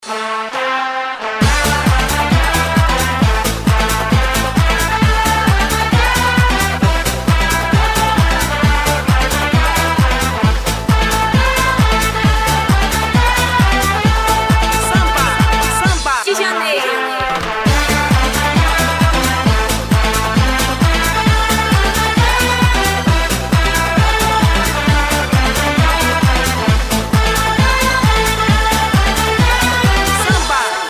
• Качество: 128, Stereo
поп
dance